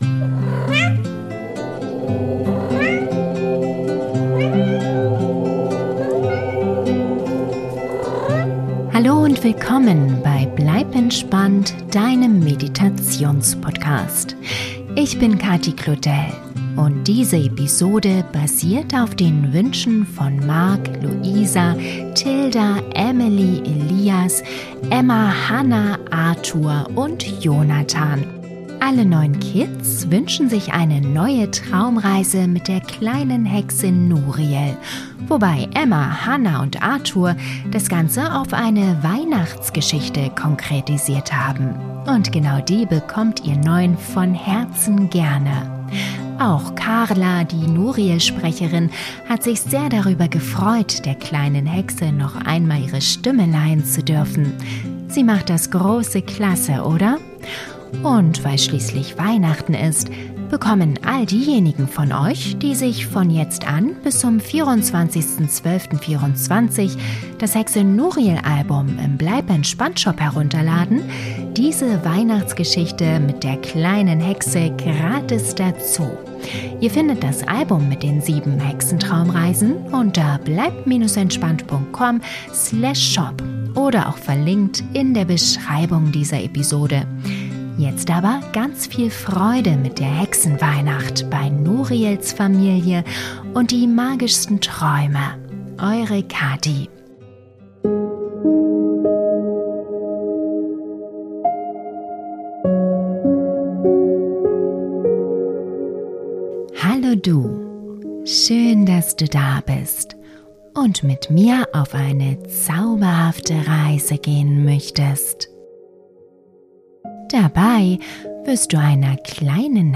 Traumreise für Kinder zum Einschlafen - Hexe Nuriel feiert Weihnachten - Hexen Geschichte ~ Bleib entspannt! Der Meditations-Podcast - magische Momente für Kinder & Eltern Podcast